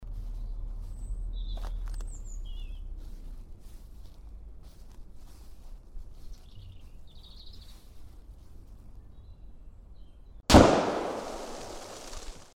ΜΕ SOUNDZ ( Ακούς καθαρά τα πουλιά και όλους τους ήχους και πολύ χαμηλά τον πυροβολισμό )
Shooting-DI-Beige.mp3